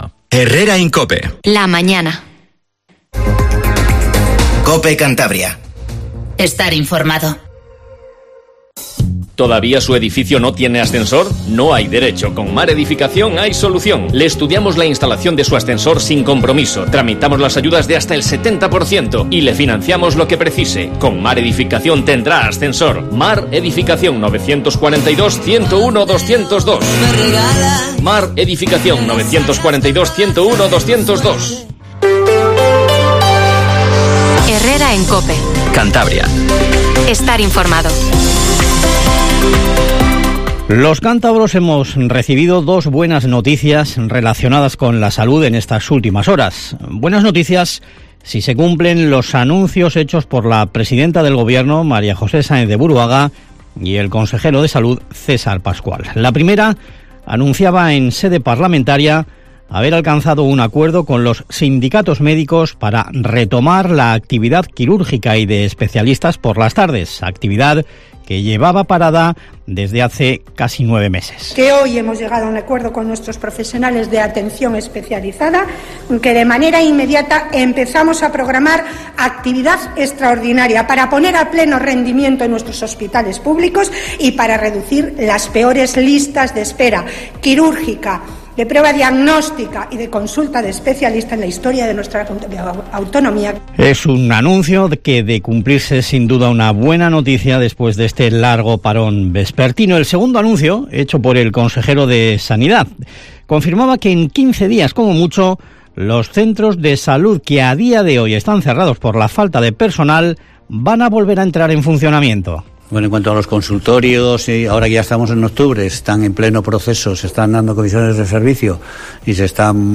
Informativo HERRERA en COPE CANTABRIA 07:20